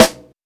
Rue_snr_4.wav